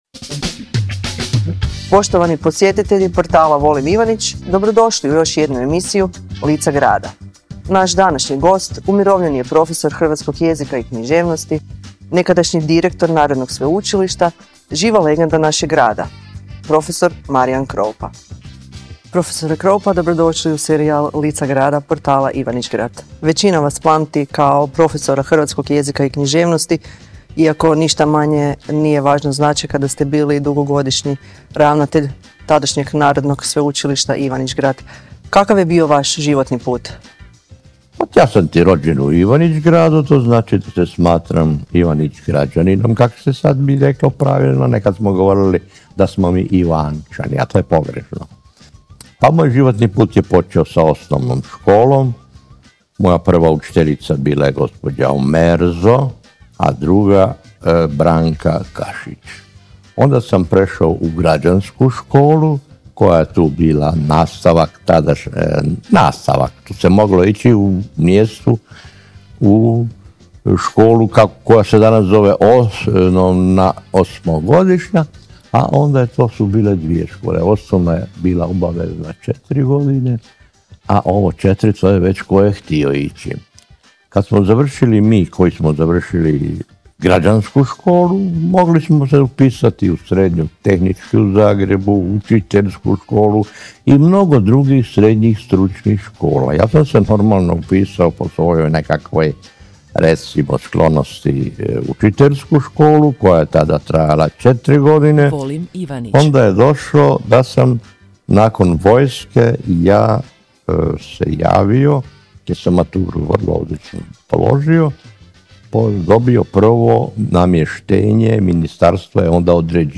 U razgovoru se osvrće na školstvo nekad i danas. Govori smireno, bez gorčine, s iskustvom čovjeka koji je cijeli život proveo u obrazovanju.
Originalni audio zapis razgovora